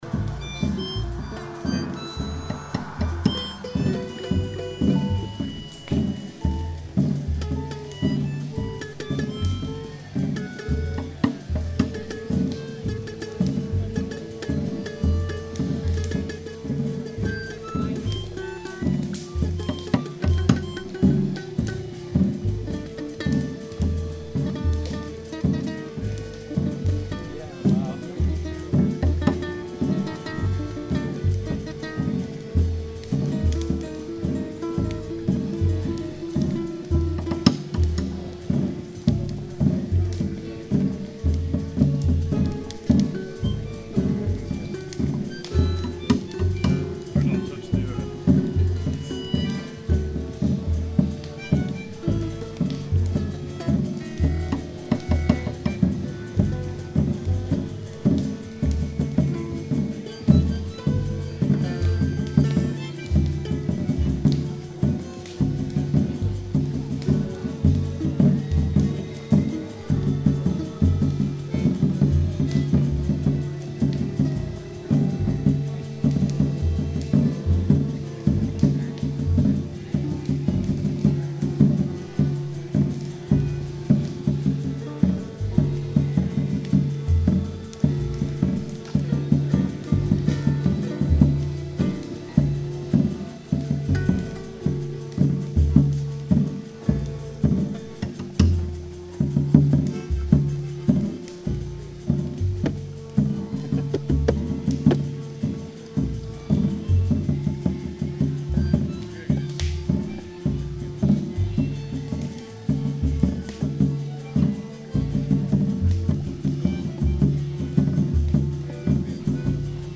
Location: Lake Clara